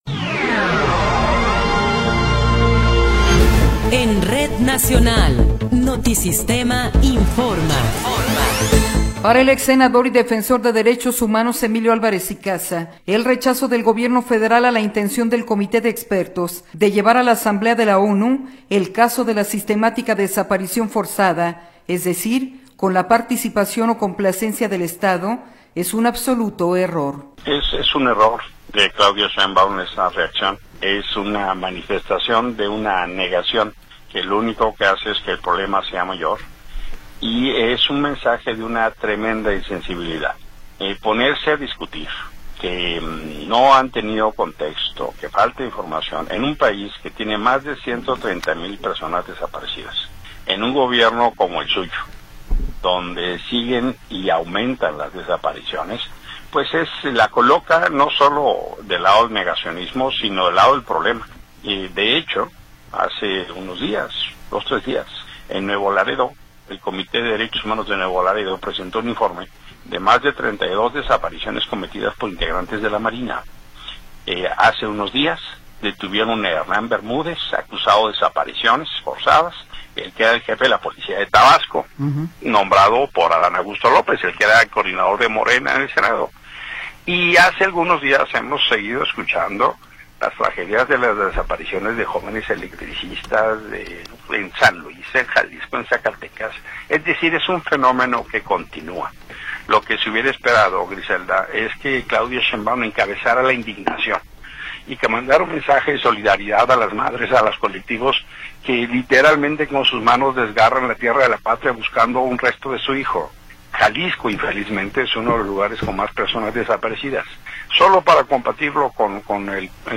Noticiero 21 hrs. – 19 de Abril de 2026
Resumen informativo Notisistema, la mejor y más completa información cada hora en la hora.